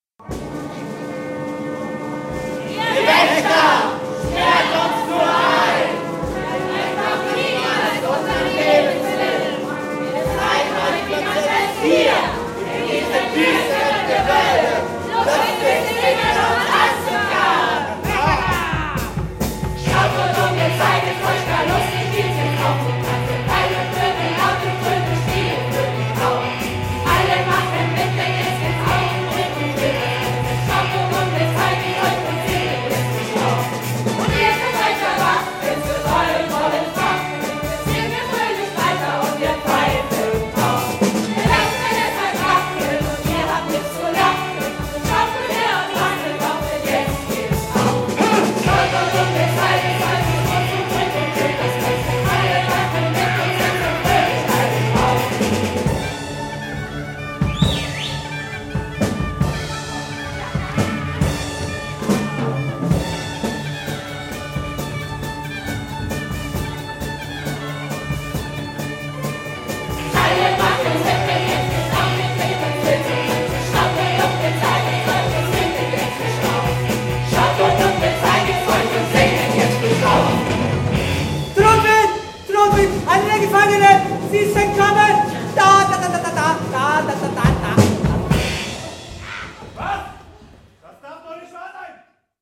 Sie lenken die anderen Mitinsassen mit einem Spottlied und Tanz ab: